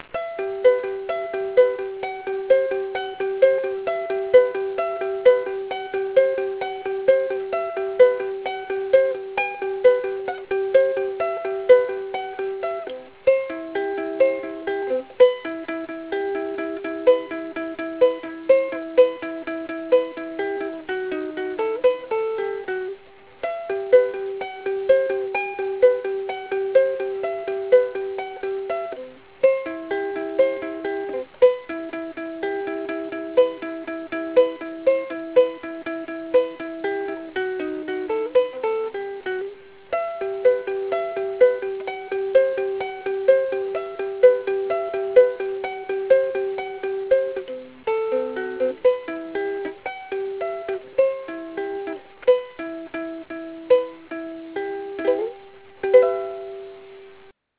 かの有名な「さくらさくら*1」を ウクレレ用に編曲してみました。
単音弾き編 (57s)